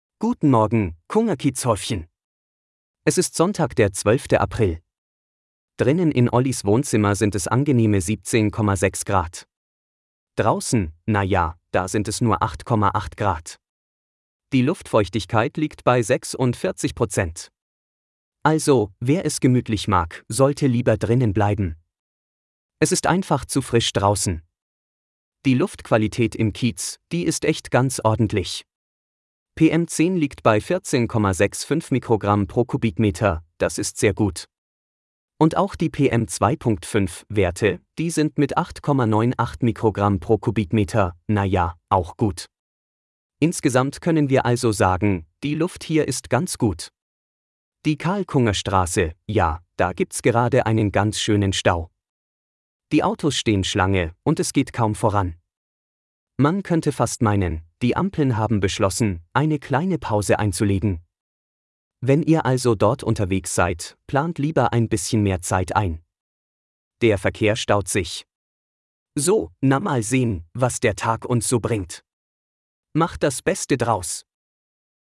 Ein automatisierter Podcast für den Kungerkiez
Nachrichten , Gesellschaft & Kultur